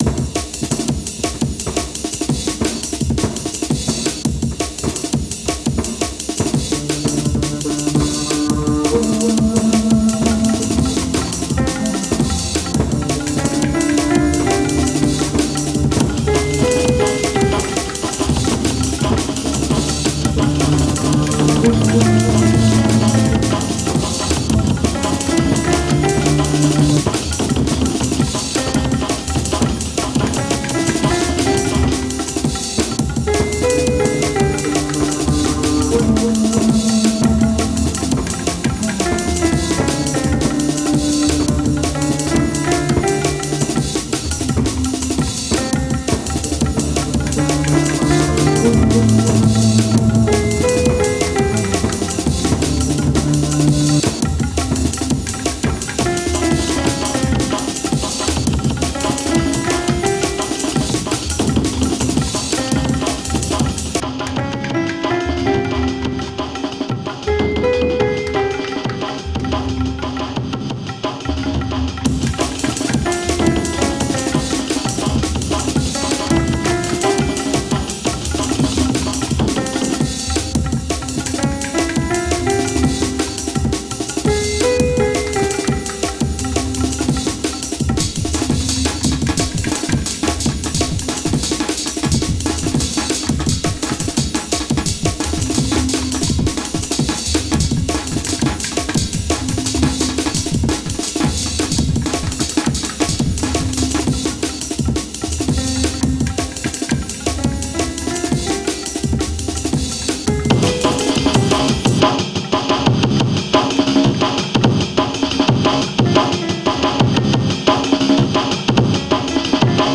Click for snazzy desert music!